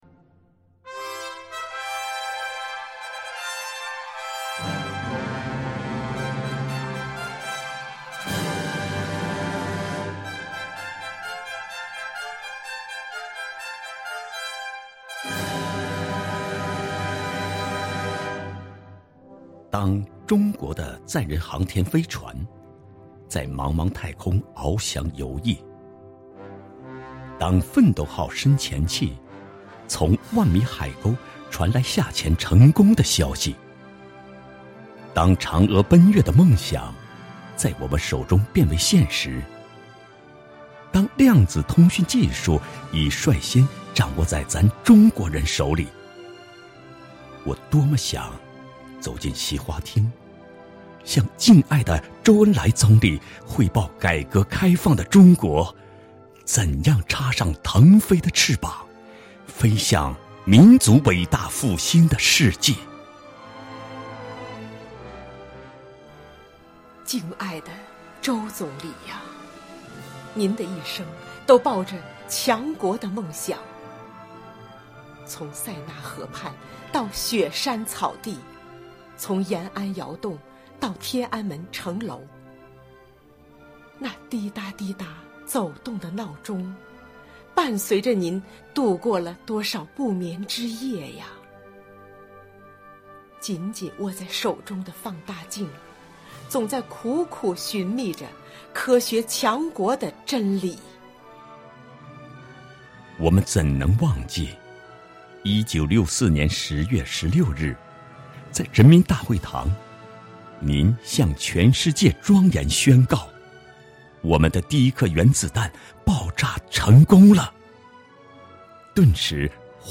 朗诵——中国，再给世界一个新的奇迹